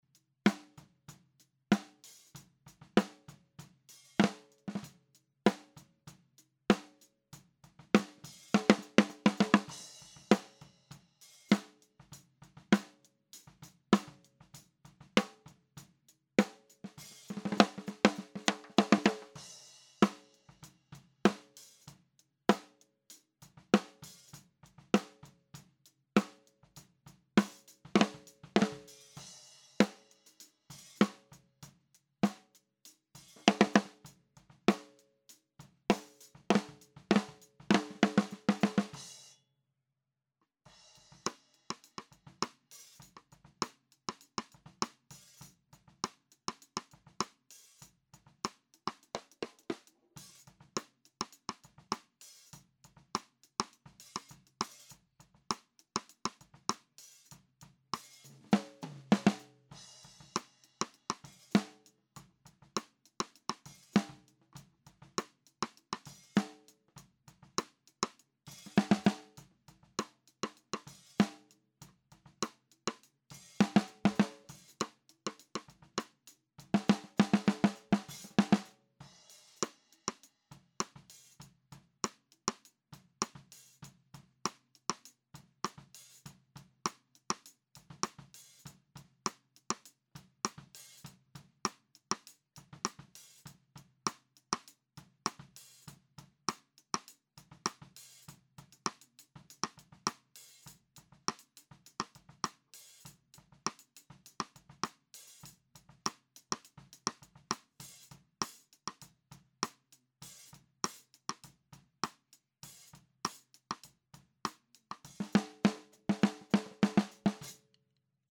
Stejný bubínek při klasickém snímání „shora“ (použitý mikrofon EV ND46)